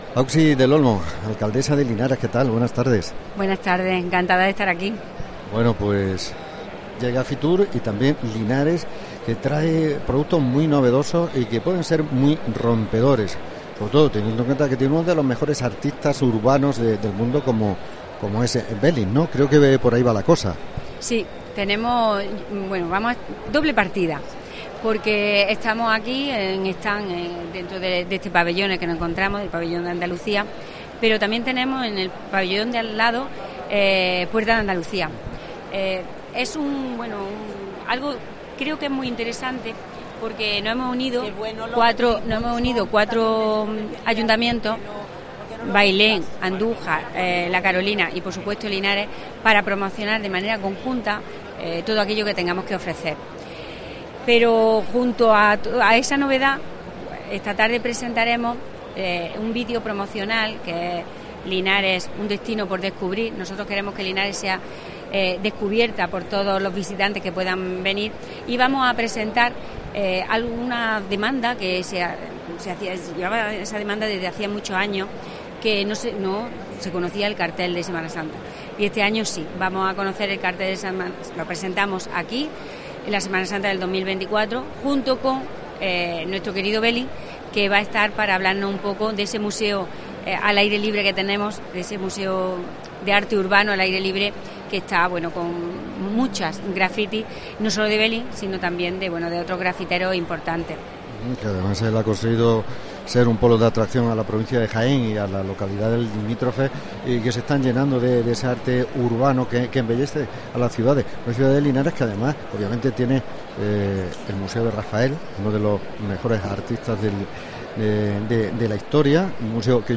Entrevistamos a la alcaldesa de Linares, auxi del Olmo en la Feria Internacional de Turismo, FITUR 2024
Entrevista con la alcaldesa de Linares en FITUR 2024